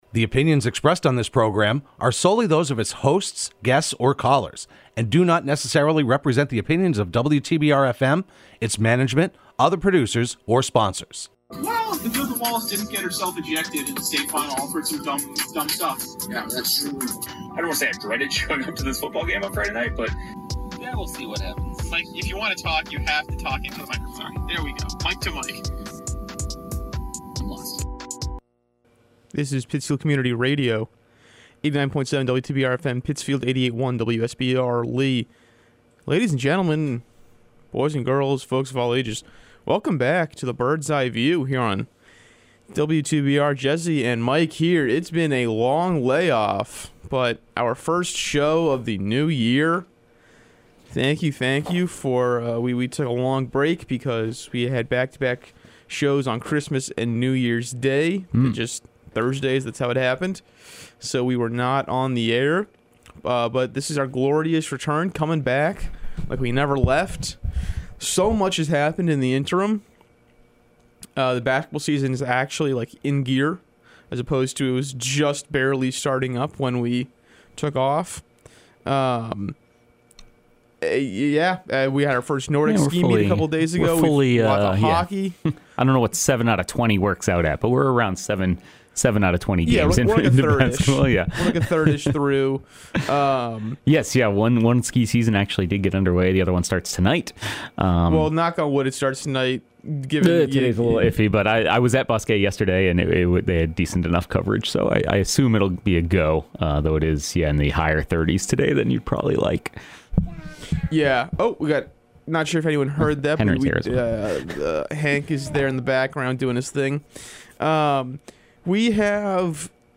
Broadcast live every Thursday morning at 10am on WTBR.